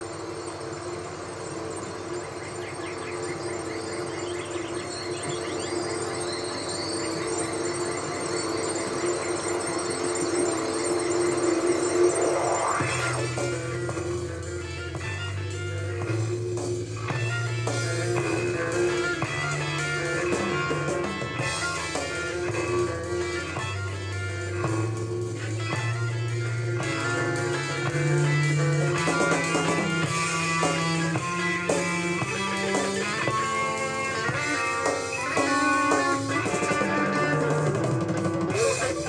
低音が急に大きくなった時に、ギターの音が小さくなったり、大きくなったり．．．
謎の音質劣化！
前述の、CD、NAS、Room Fitの時の音源(空気録音)は、このマイクをPCに繋いで録っております。